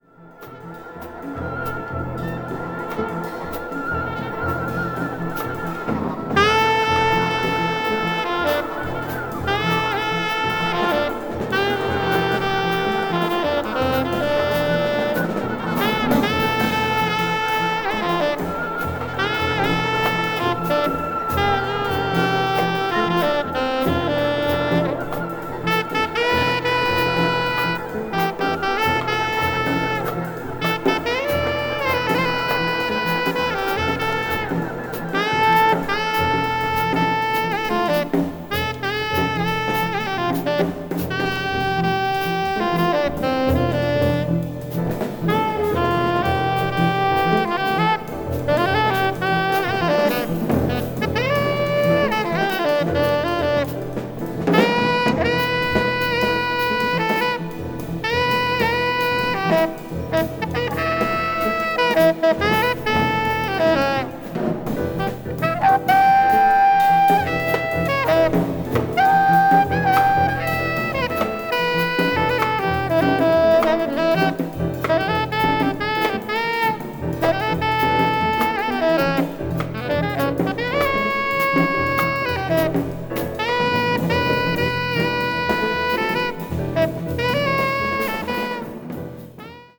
media : EX/EX,EX/EX(some slightly noises.)